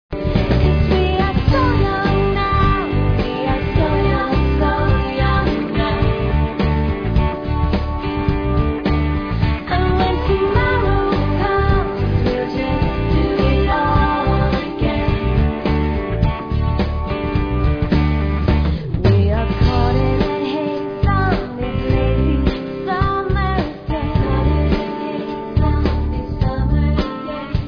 (Unmixed)